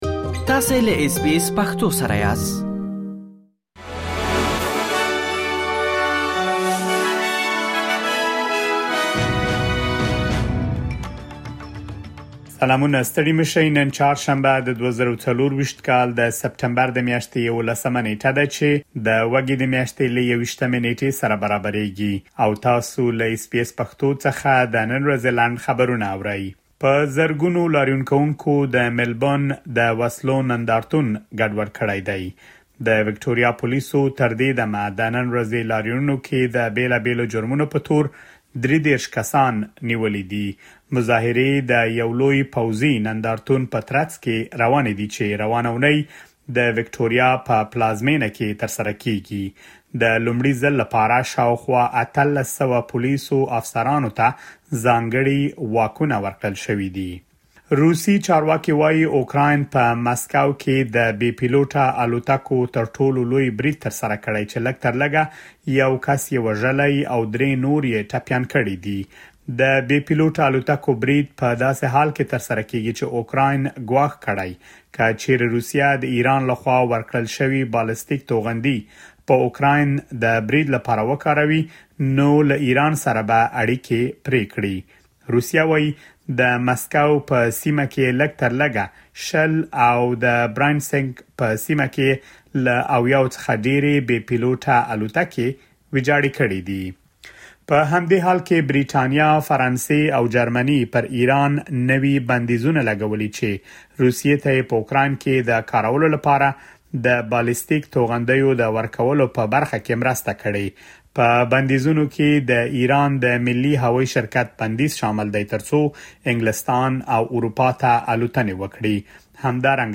د اس بي اس پښتو د نن ورځې لنډ خبرونه|۱۱ سپټمبر ۲۰۲۴